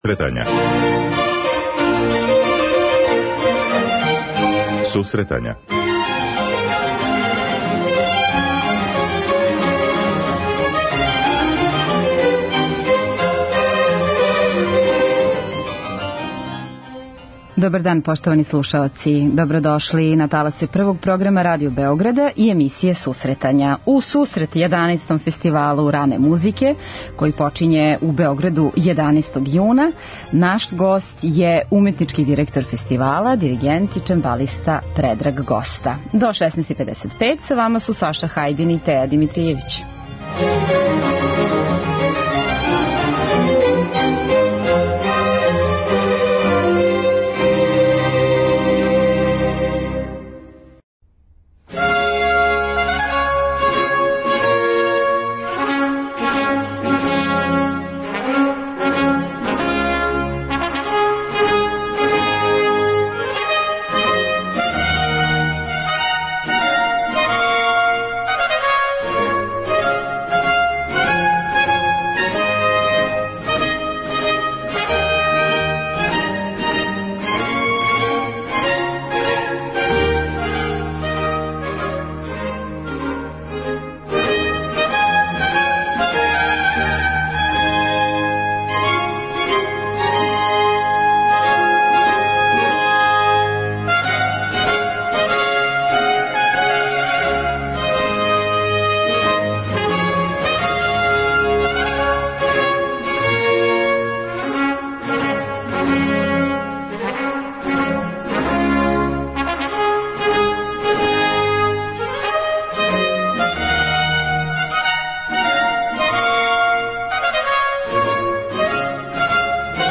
преузми : 9.90 MB Сусретања Autor: Музичка редакција Емисија за оне који воле уметничку музику.